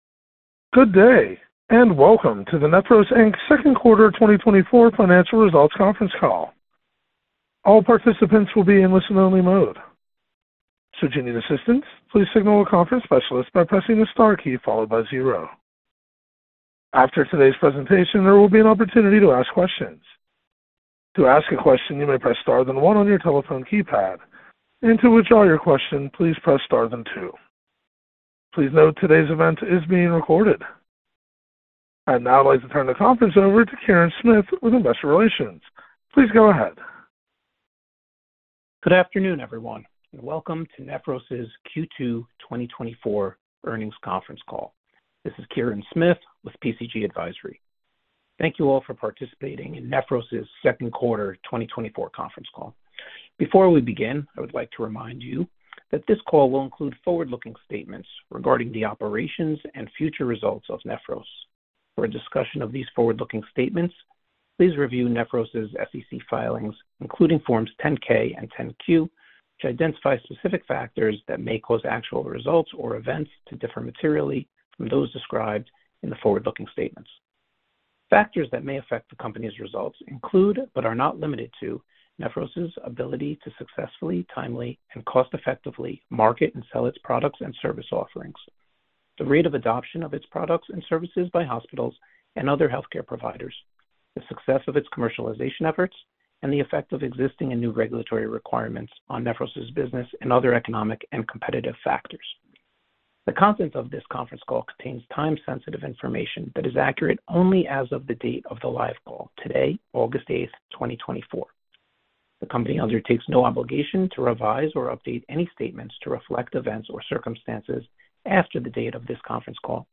Listen to the replay of the Q2 2024 conference call